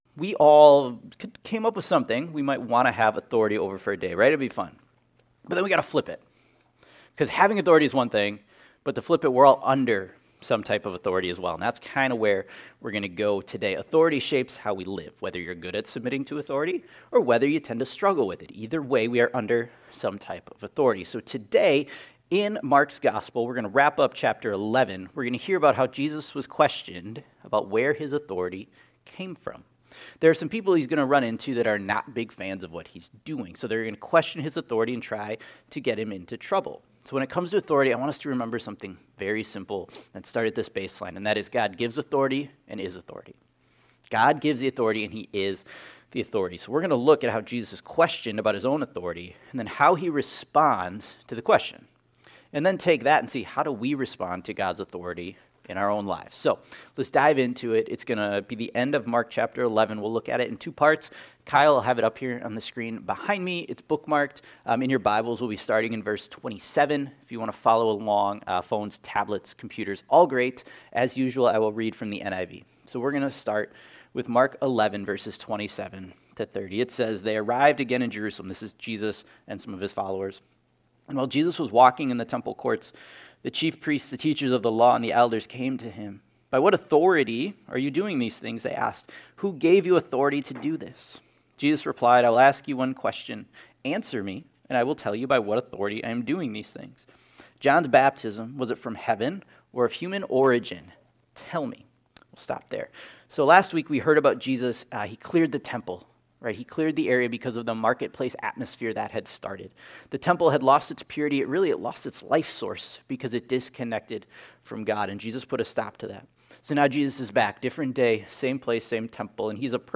Mark Acting Authority Disciple Discipleship Traps Sunday Morning In the closing section of Mark 11, Jesus' authority was questioned by the Sanhedrin.